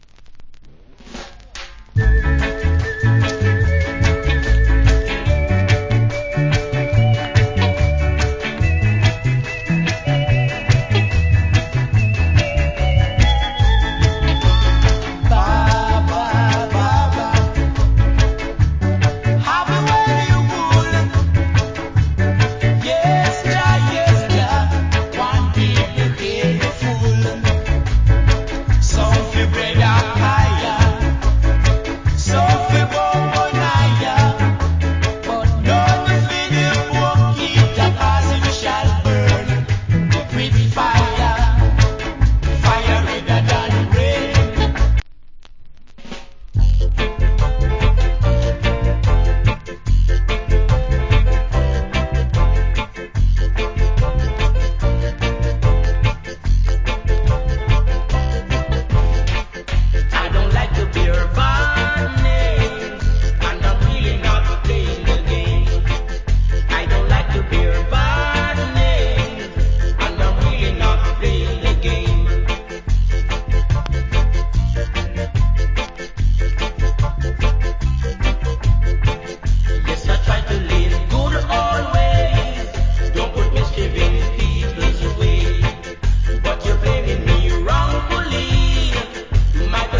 Nice Early Reggae Vocal.